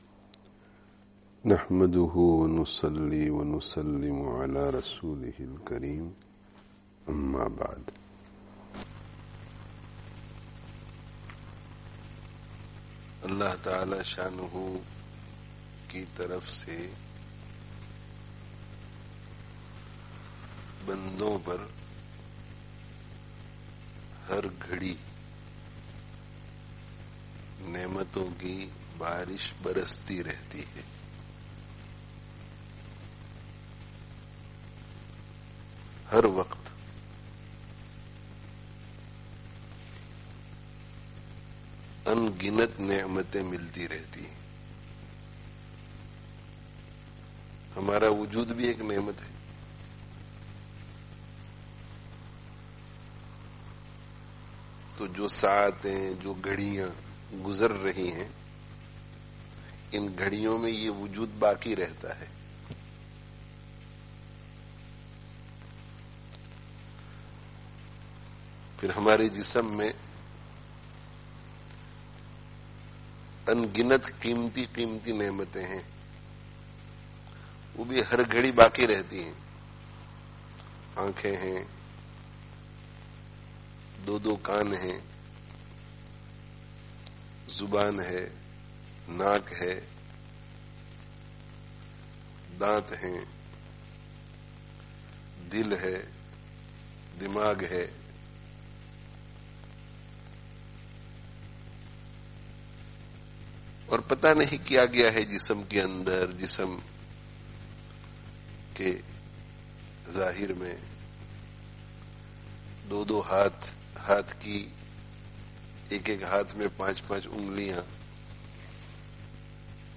friday tazkiyah gathering